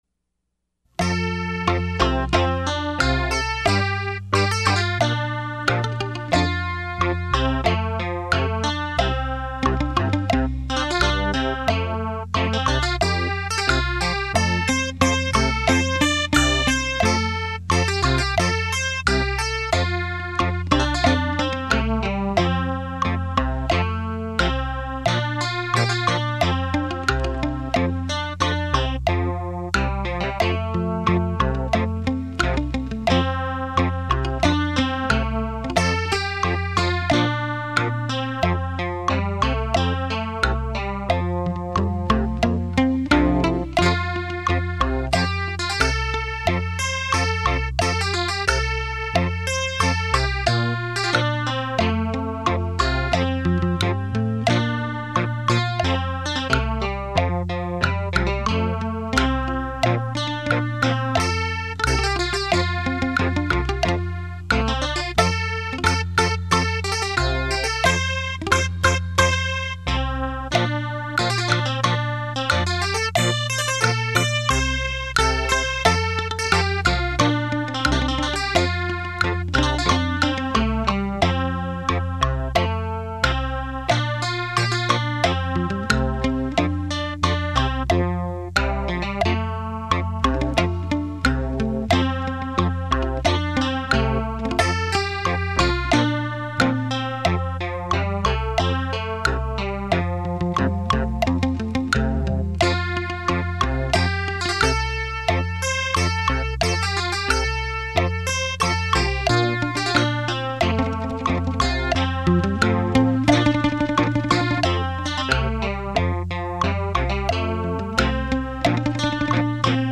汽车音响测试碟
立体音声 环绕效果